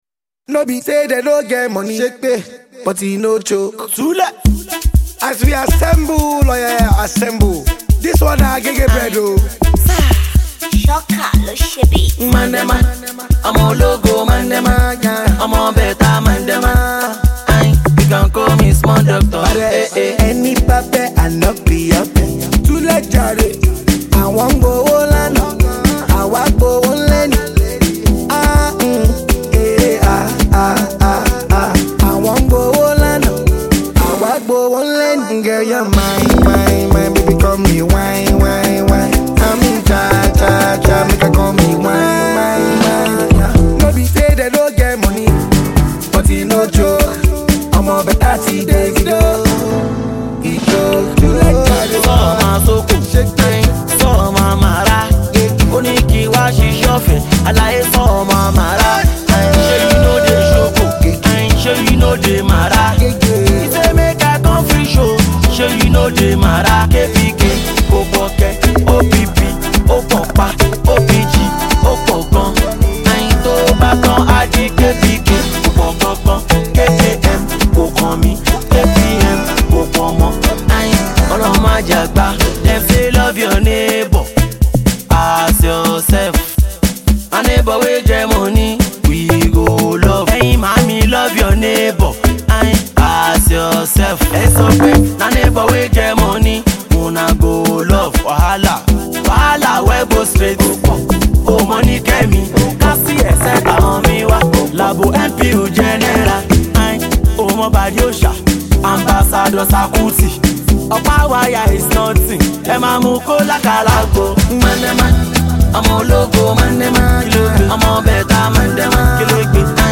Street Inspired Nigerian indigenous singer